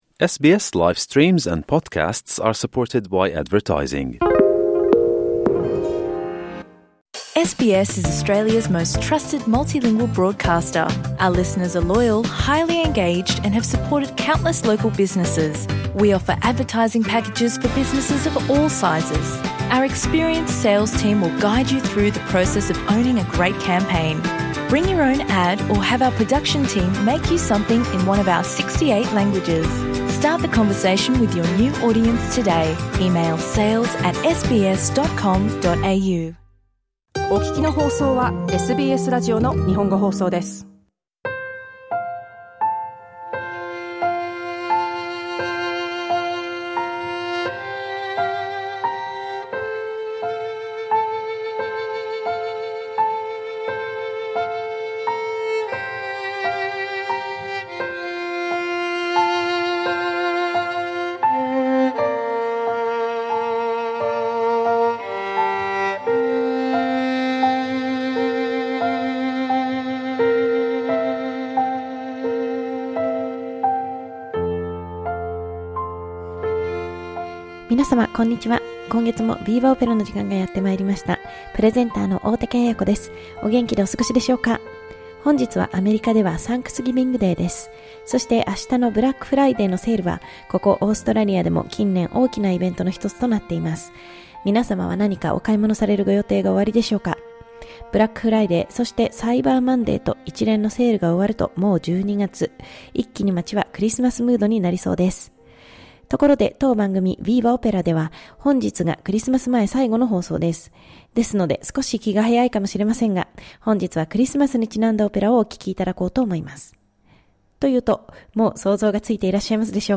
クリスマス前の最後の放送ということで、今年も恒例の「ラ・ボエーム」から、華やかな一曲を選びました。